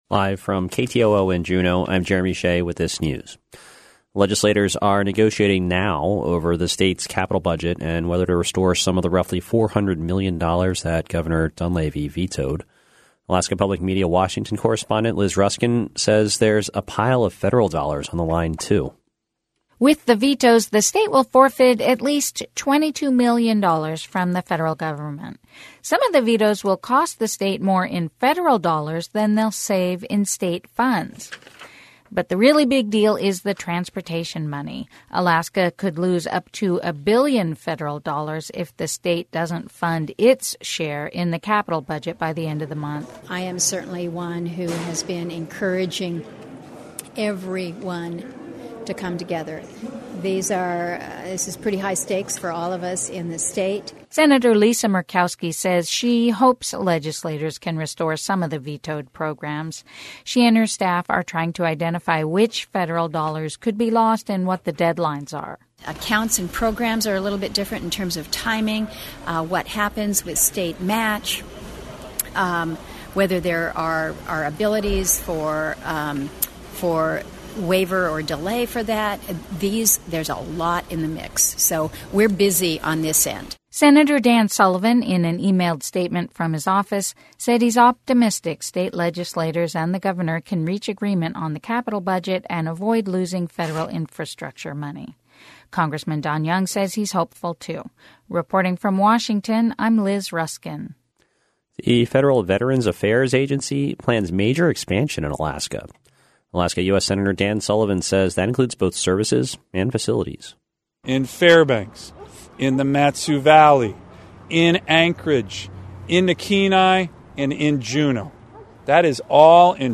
Newscast – Friday, July 19, 2019